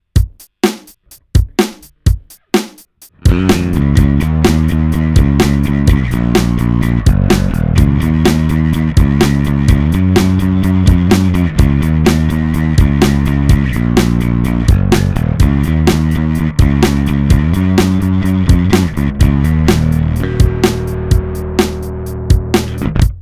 Zoom B3 - zkreslení:
Overdrive + comp + eq
160 comp: -25;2.2;6;hard;64
overdrive: 0;0;58;16
graph. eq: pouze potlačena pásma - 800hz -4; 405khz -4; 10khz -8; level 100